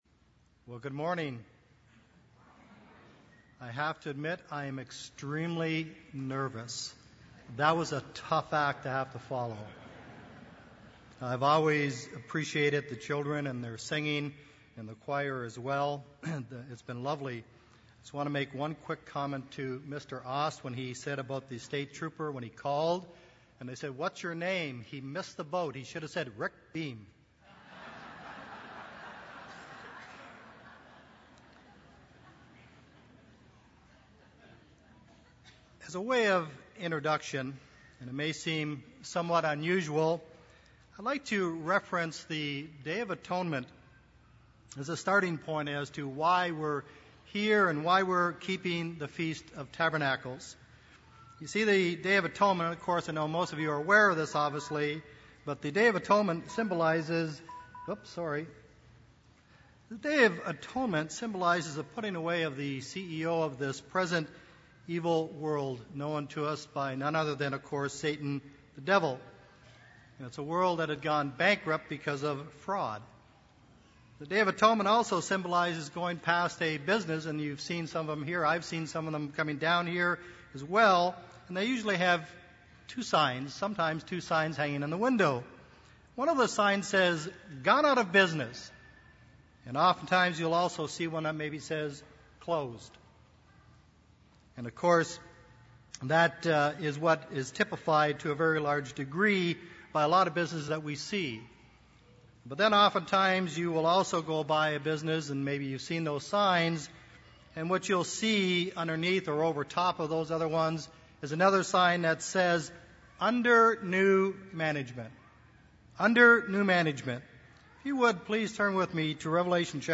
This sermon was given at the Panama City Beach, Florida 2014 Feast site.